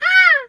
bird_peacock_scream_01.wav